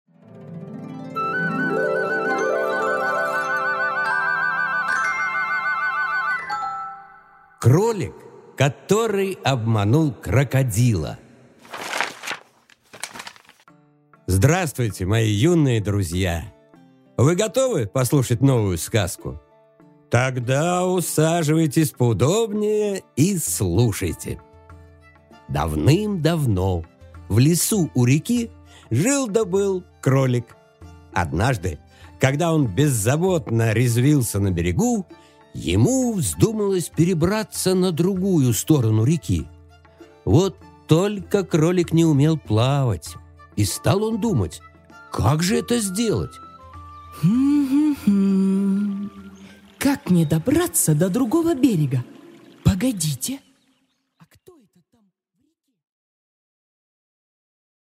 Аудиокнига Кролик, который обманул крокодила | Библиотека аудиокниг